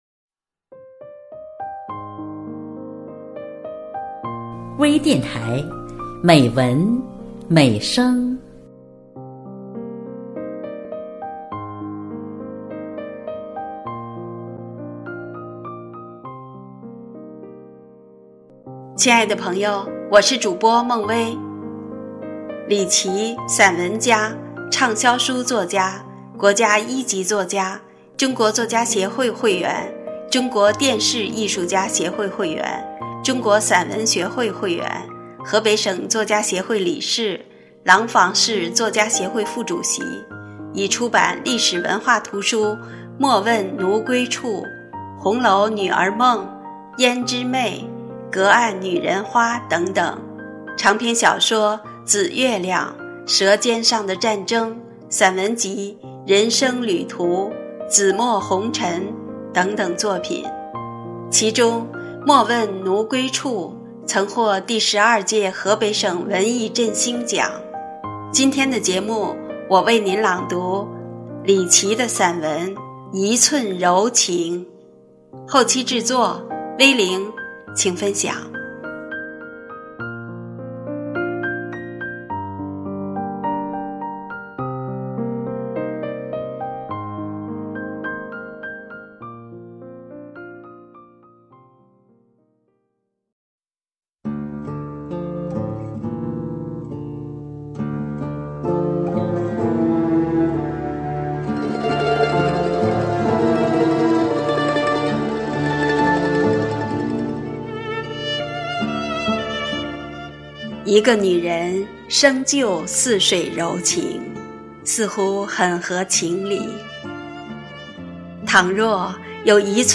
多彩美文  专业诵读